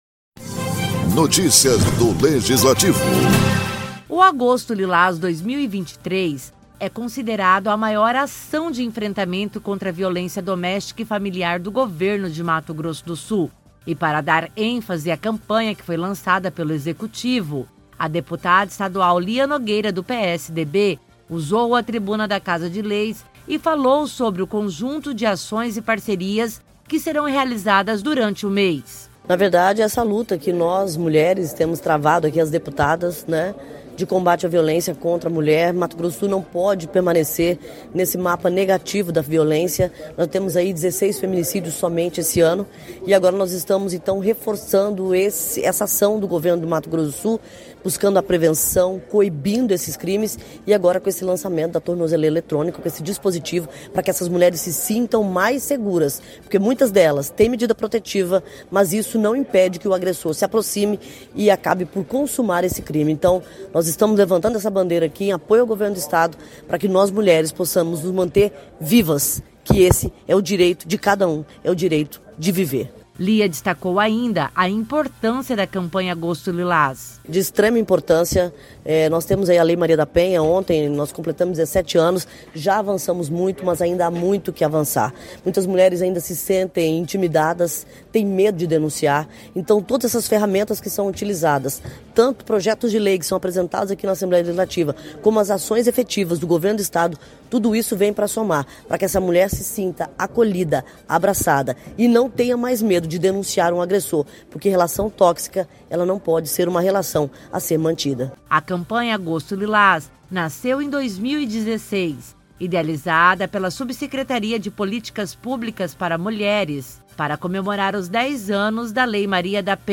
Deputada repercurte lançamento da campanha "Agosto Lilás 2023" na tribuna da ALEMS
A deputada estadual Lia Nogueira, do PSDB, usou a tribuna da ALEMS para dar ênfase a maior ação de enfrentamento à violência doméstica e familiar do Estado  de Mato Grosso do Sul, o "Agosto Lilás 2023", realizada pelo Governo do Estado.